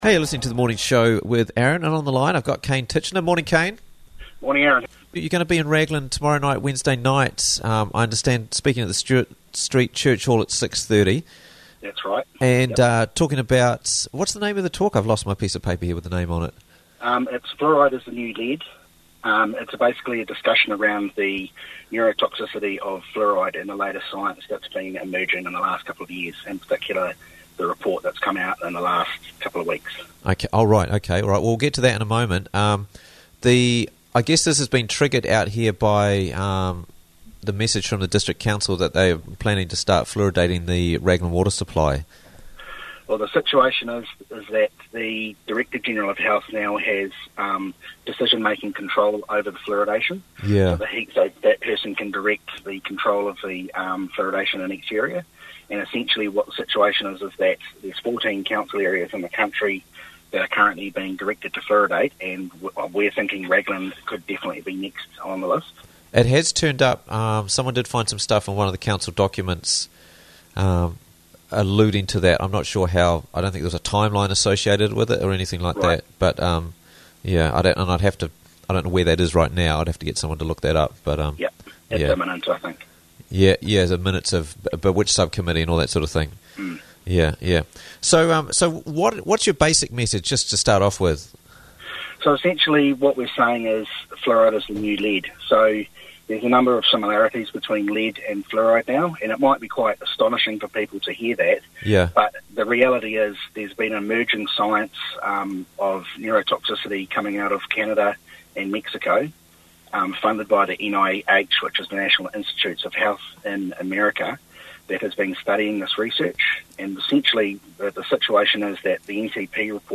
Fluoride Talk Wednesday - Interviews from the Raglan Morning Show